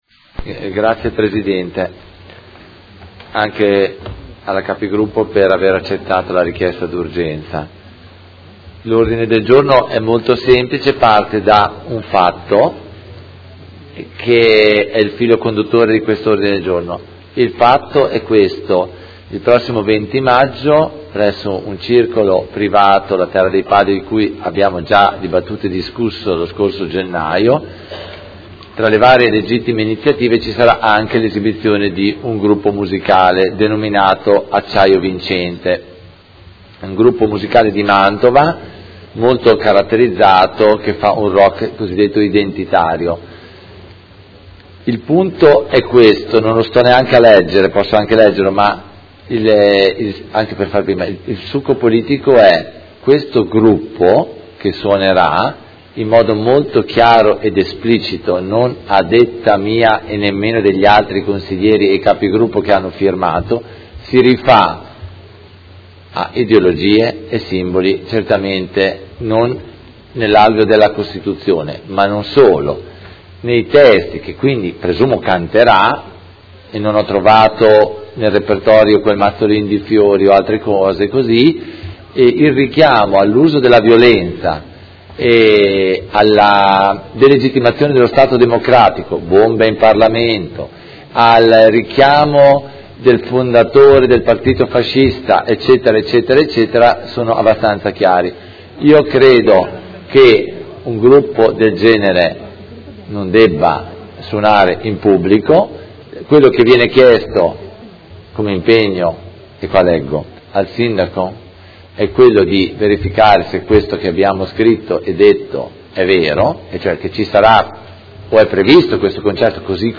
Antonio Carpentieri — Sito Audio Consiglio Comunale